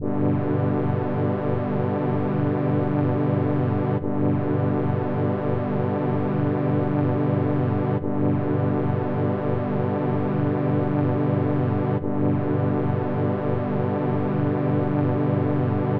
drone-trombone.wav